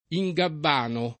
ingabbanare v.; ingabbano [ i jg abb # no ]